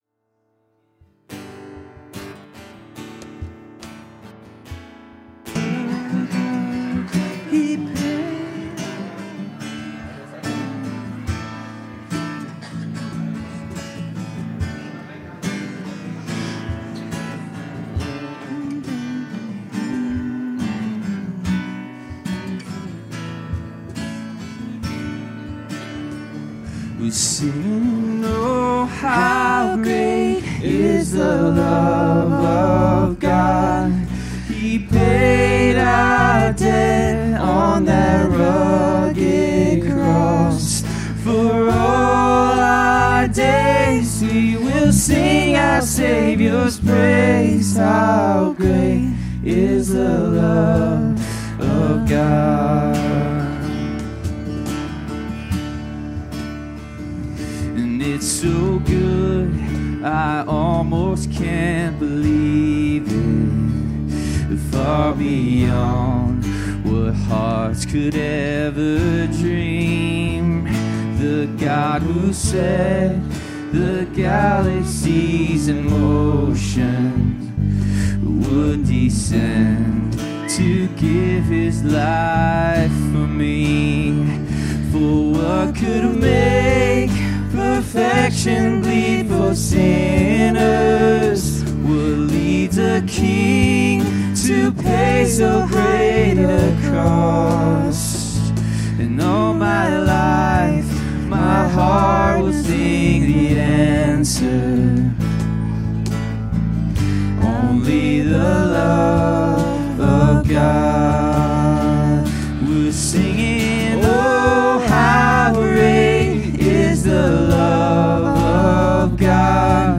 Worship 2025-04-06